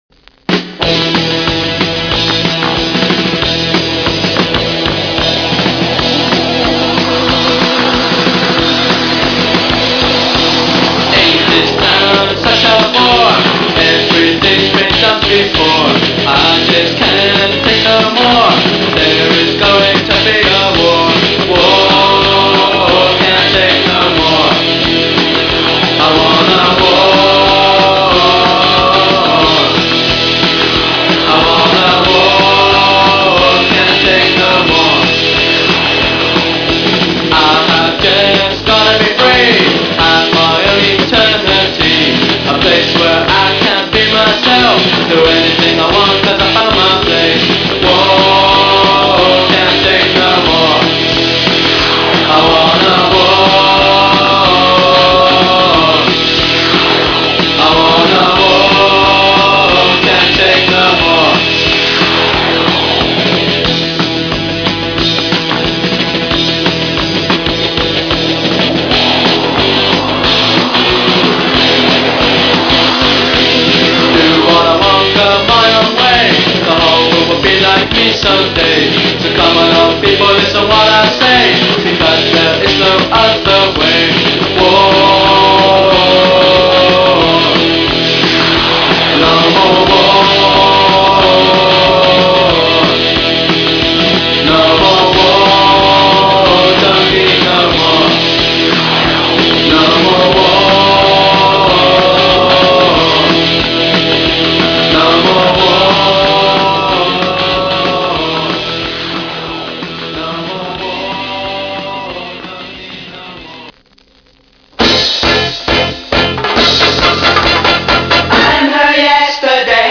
/power popコレクタブル